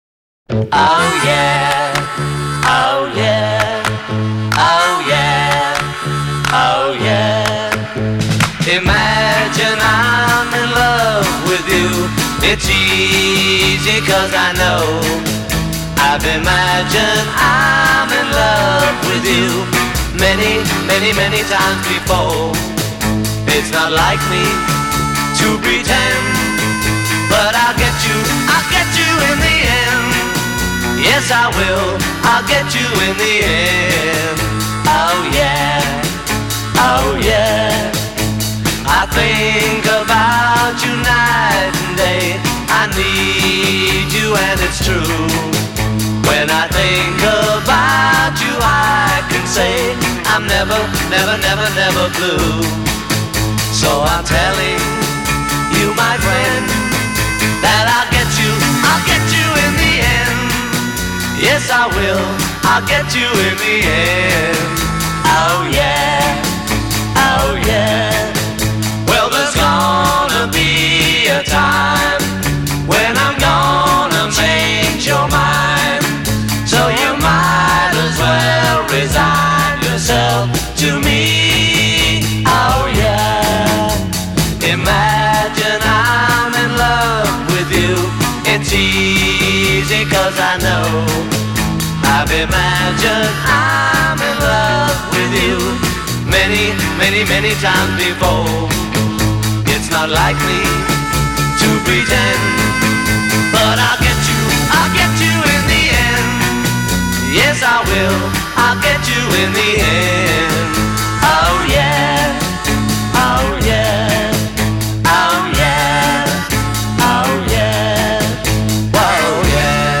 Песня записывалась в спешке и, видимо, было не много дублей
губная гармоника
хлопки в ладоши